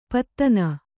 ♪ pattana